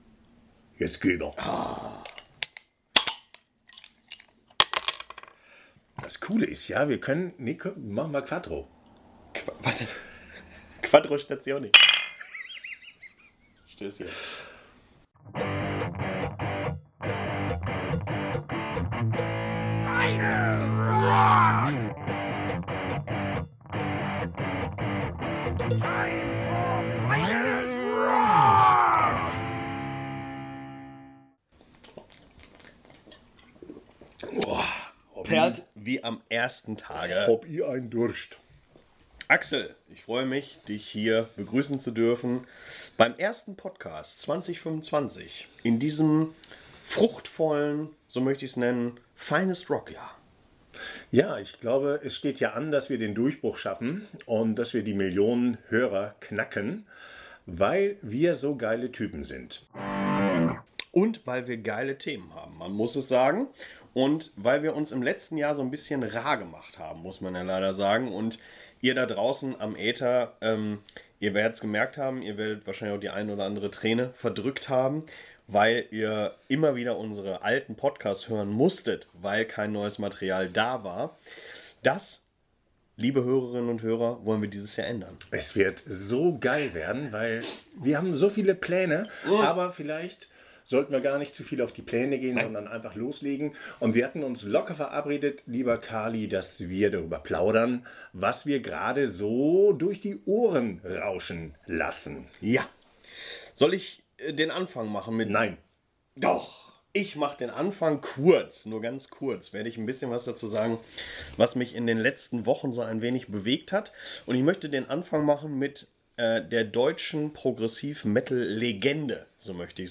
Freut Euch auf hartes Geplauder auf höchstem Niveau, mit dollstem Stoff.